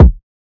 Kick (Legend).wav